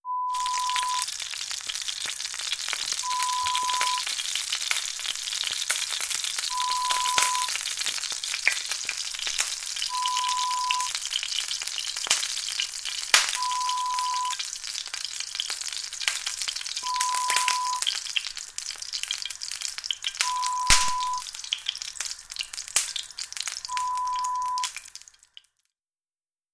Sonido de freir (estar friendo). Acompaña a dicho sonido un pitido espaciado y constante no relacionado con dicha actividad
Sonidos: Acciones humanas